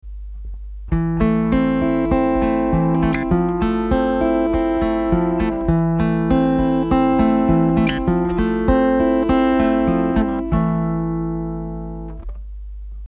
more of a classical sound